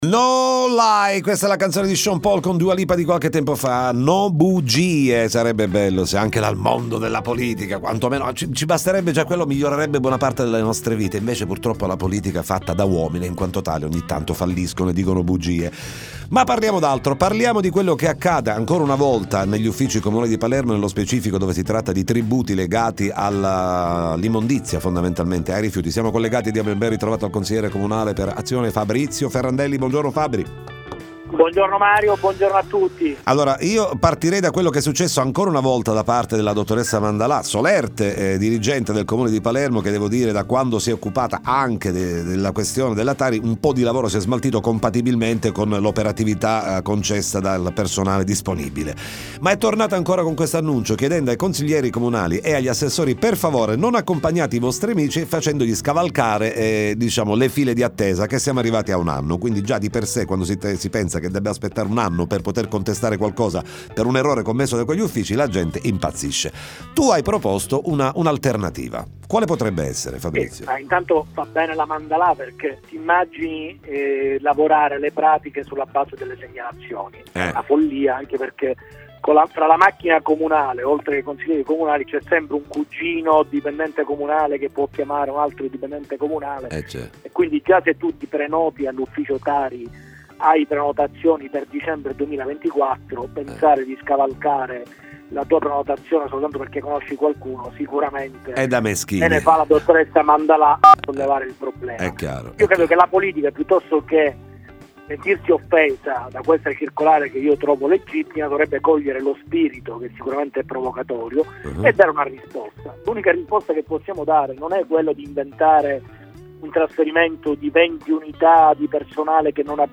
Time Magazine intervista Fabrizio Ferrandelli